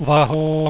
wahoooooo.wav